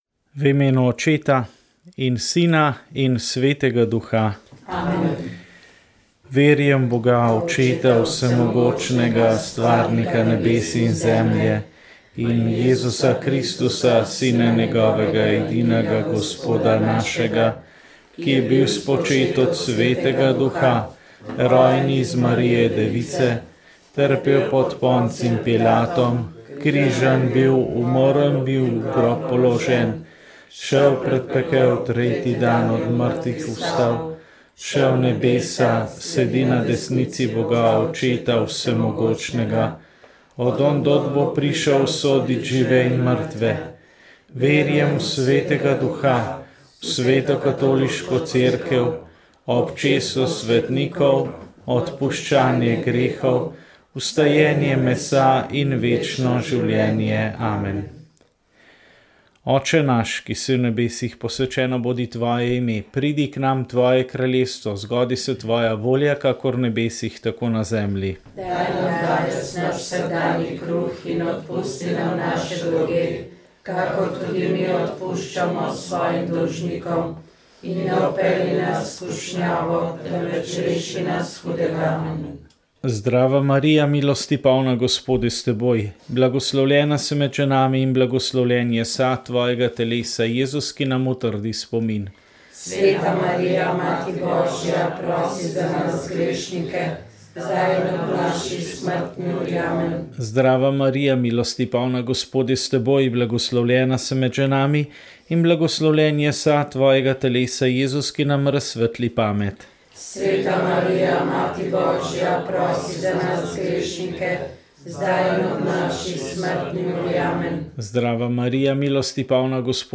Rožni venec